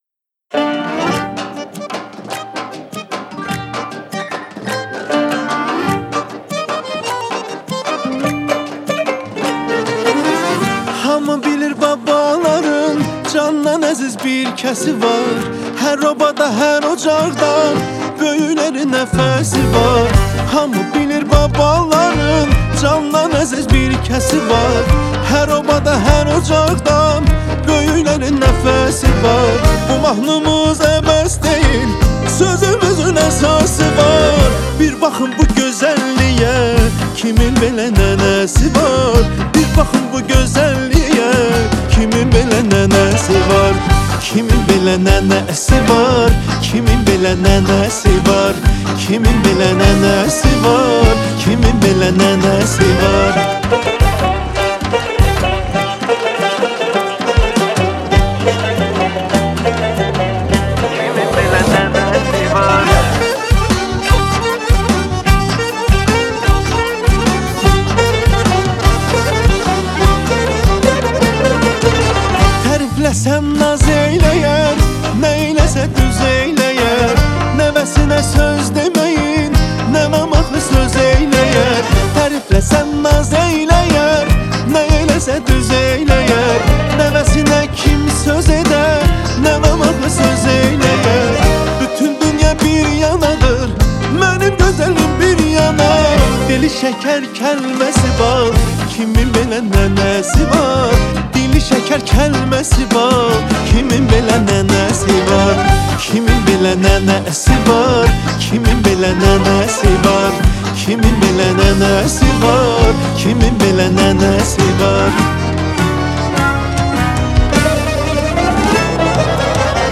آهنگ آذربایجانی آهنگ شاد آذربایجانی